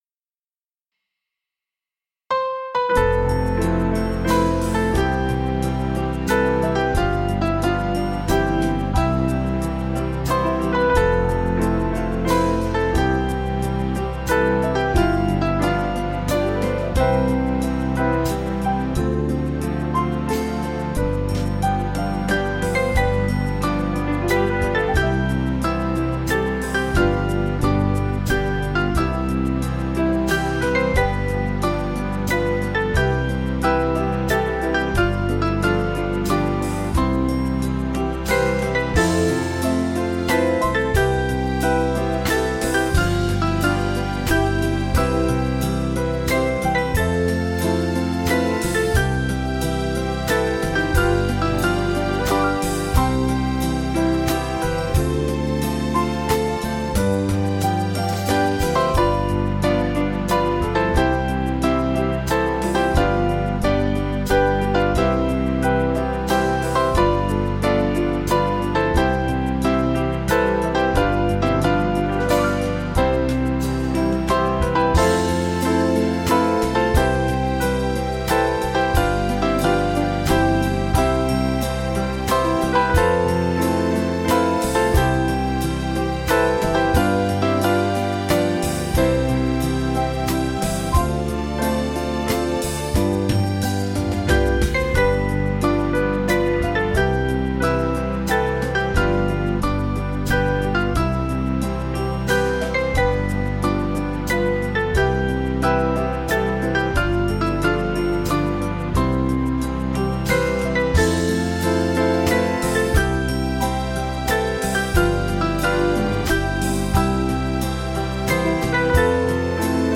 Small Band
Slight lilt   489.1kb